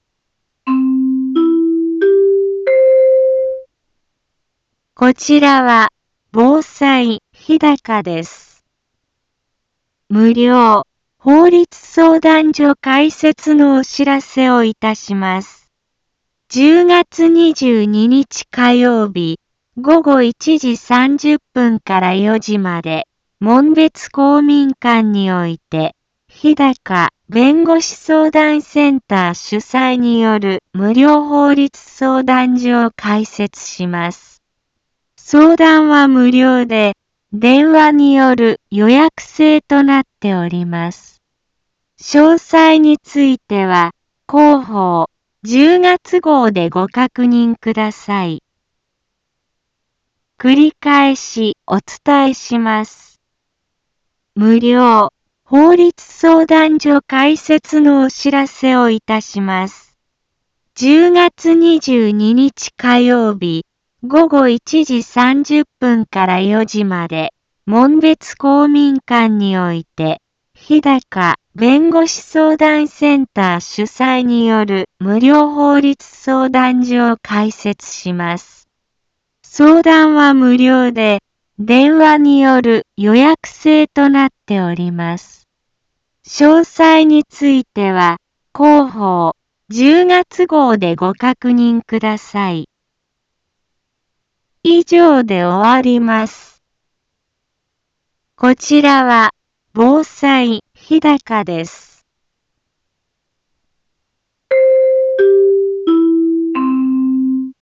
Back Home 一般放送情報 音声放送 再生 一般放送情報 登録日時：2024-10-17 10:03:46 タイトル：無料法律相談会のお知らせ インフォメーション： 無料法律相談所開設のお知らせをいたします。 10月22日火曜日、午後1時30分から4時まで、門別公民館において、ひだか弁護士相談センター主催による無料法律相談所を開設します。